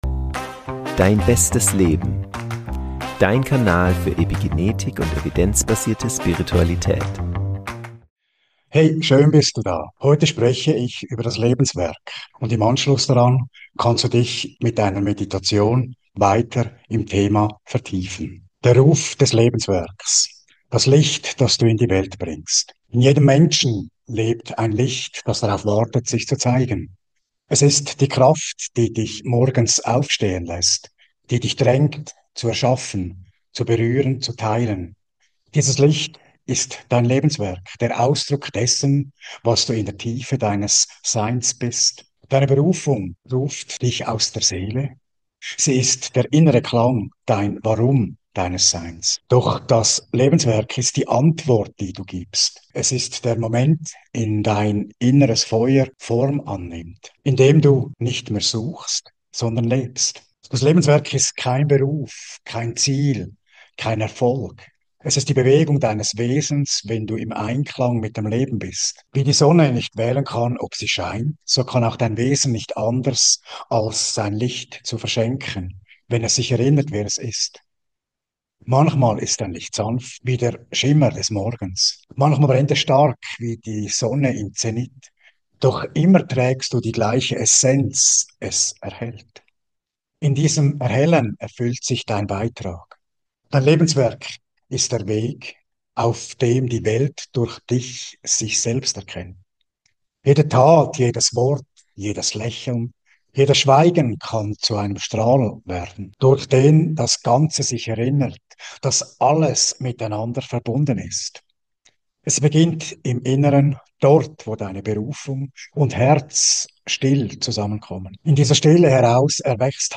Das Licht Deines Lebenswerks | Eine geführte Meditation zur inneren Entfaltung ~ Dein bestes Leben: Evidenzbasierte Spiritualität und Epigenetik Podcast